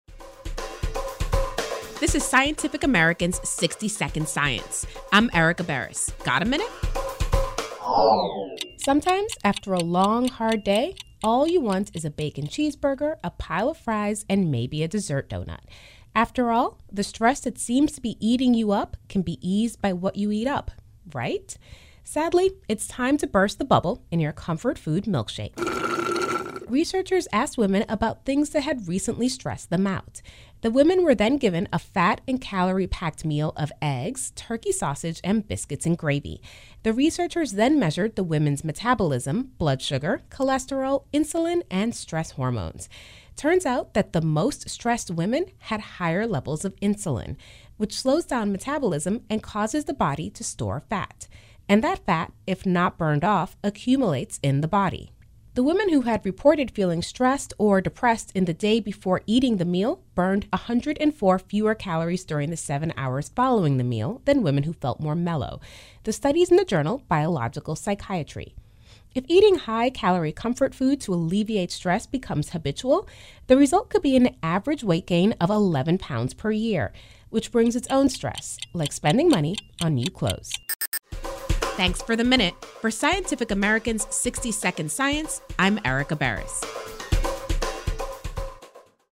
[Sound effect via SoundBible]